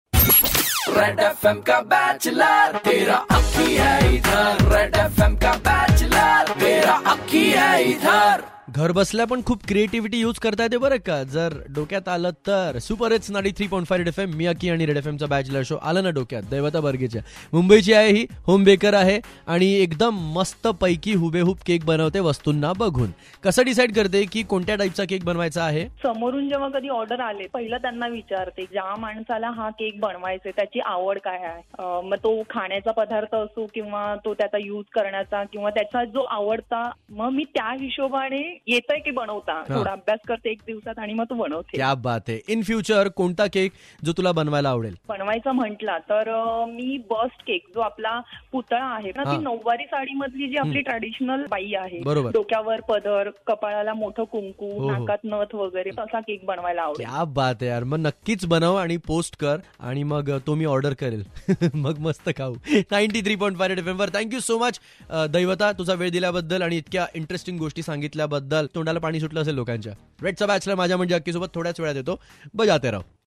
We interviewed her for more details about this !!